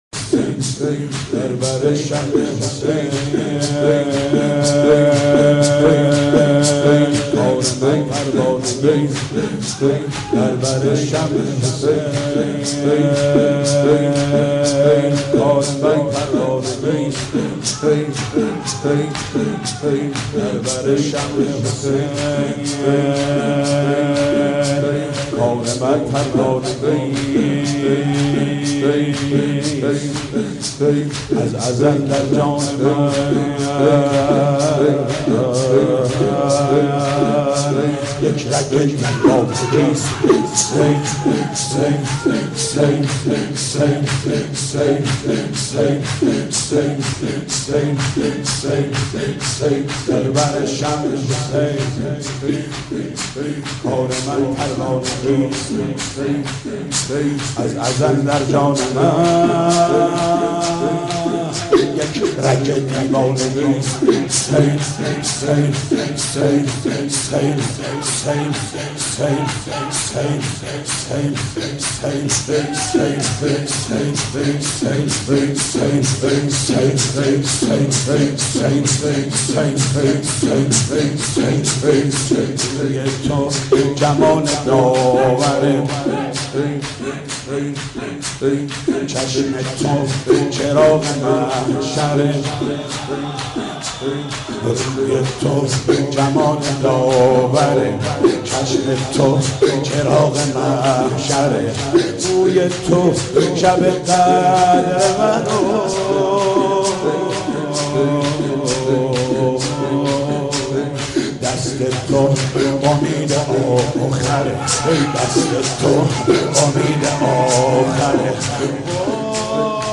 دانلود مداحی فاطمیه
سینه زنی در شهادت حضرت فاطمه زهرا(س)